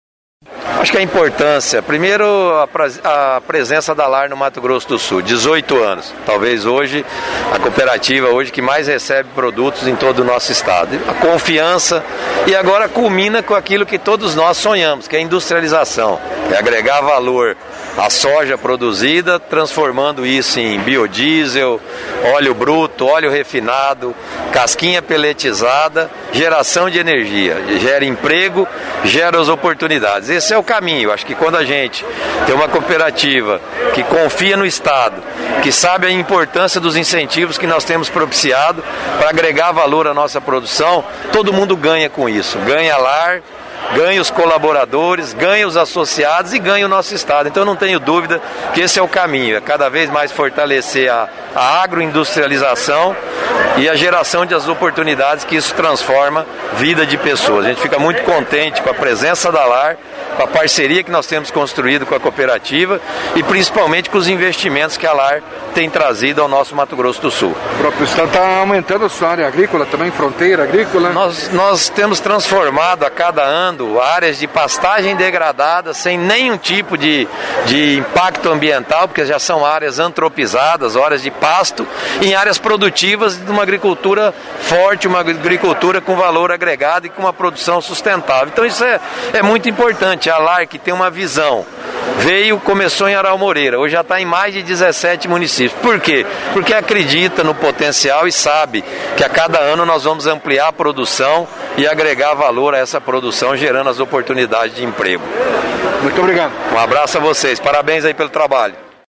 Lar Inaugura Complexo Industrial em Caarapó – MS
O Governador do Estado do MS – Reinaldo Azambuja, conhecedor das características